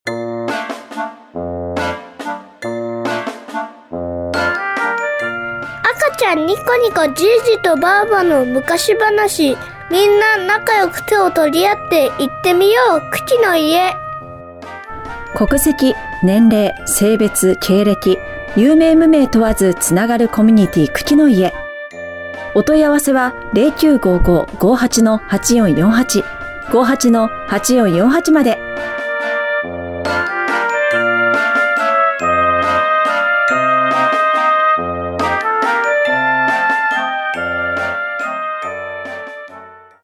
ラジオパーソナリティとして培った音声編集の技術を活かし、プロのナレーション品質でお届けします。
BGMkukiの家CM編集後.mp3